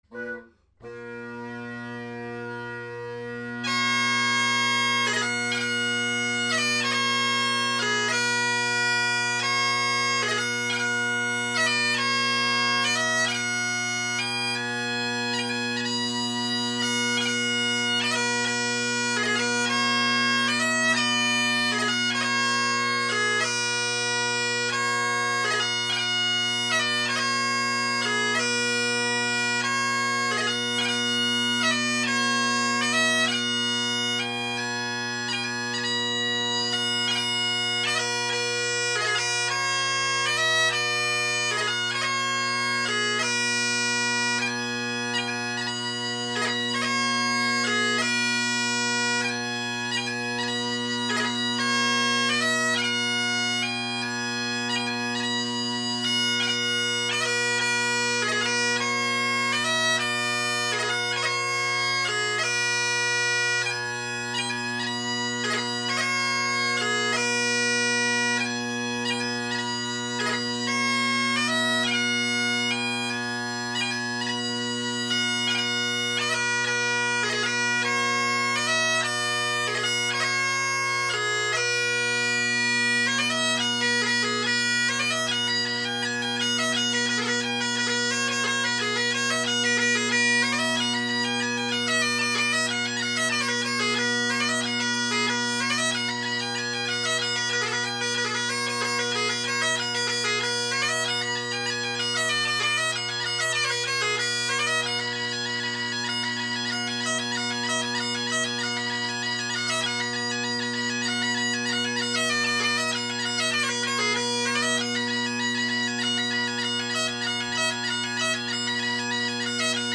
Great Highland Bagpipe Solo, Reviews